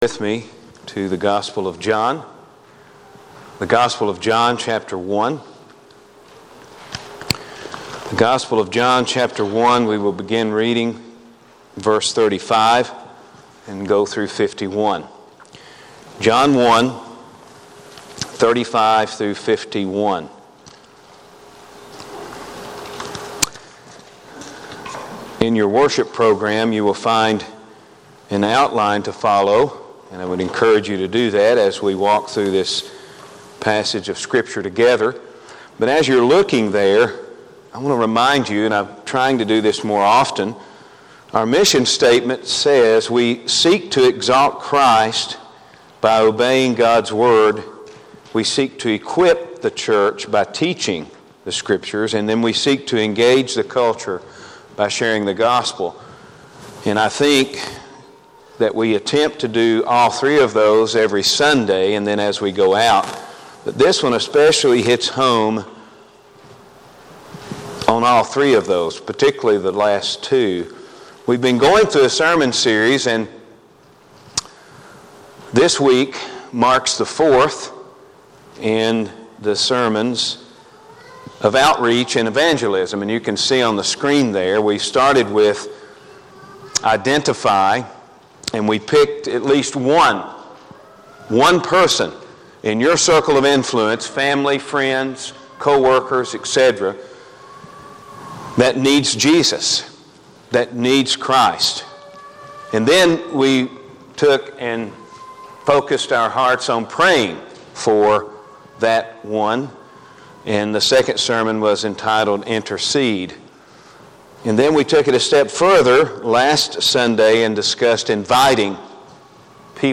Sermons - Chapel Park Baptist